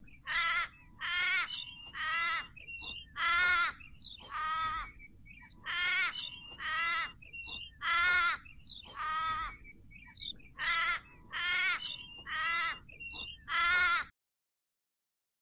Звуки кенгуру
Звук крошечного кенгуру